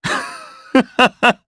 Shakmeh-Vox-Laugh_jp.wav